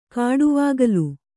♪ kāḍuvāgalu